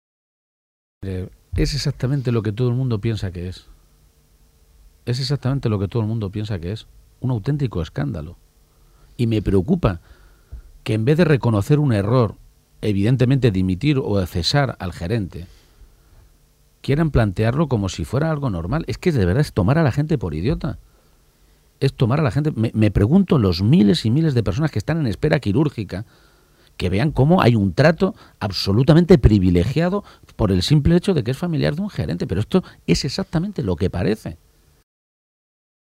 Emiliano García-Page durante la entrevista que se le ha realizado en la Cadena SER
Cortes de audio de la rueda de prensa